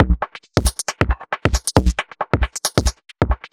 Index of /musicradar/uk-garage-samples/136bpm Lines n Loops/Beats
GA_BeatFilterB136-06.wav